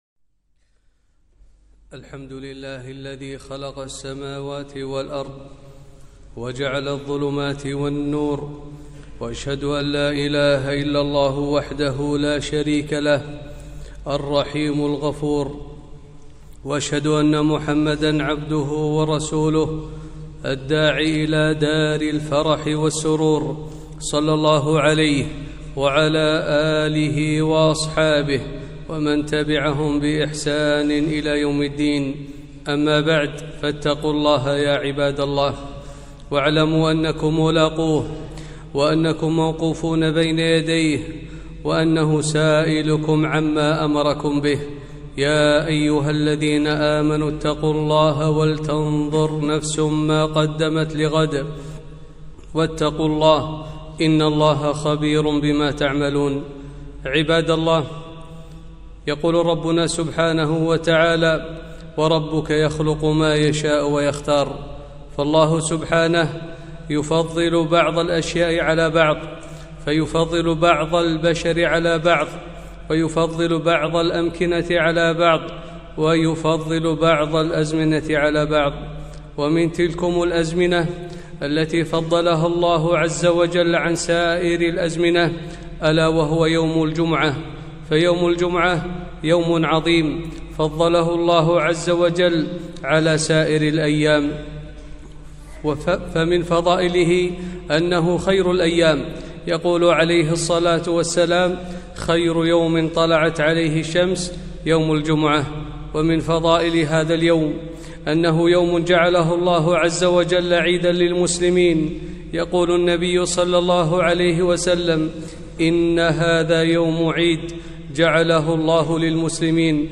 خطبة - فضل يوم الجمعة وسننه